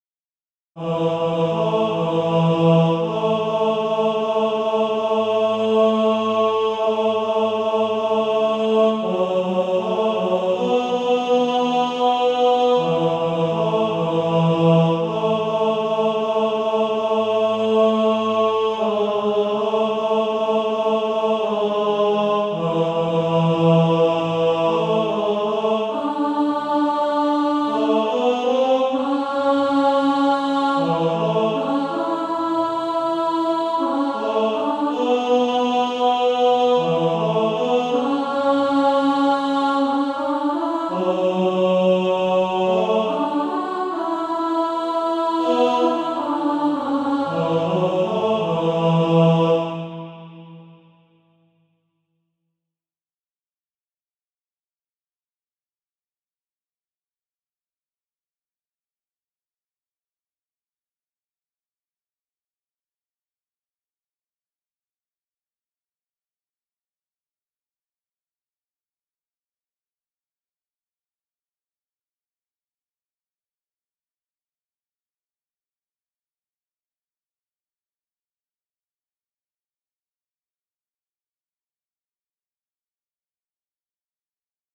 Tenor Track.
Practice then with the Chord quietly in the background.